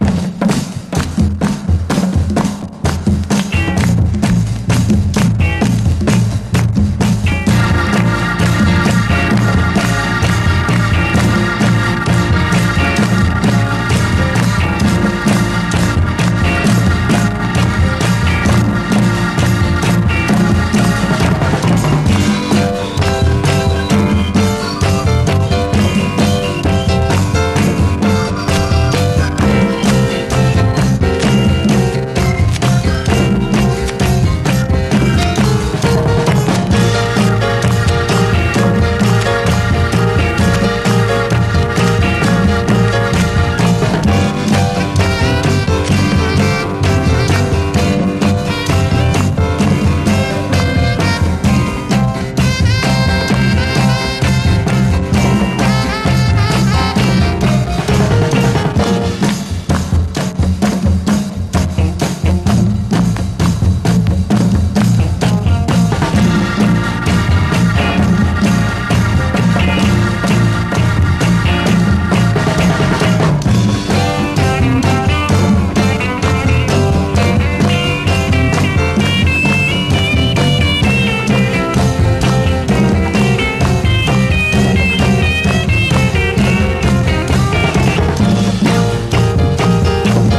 スレ・周回ノイズ箇所あるため試聴でご確認ください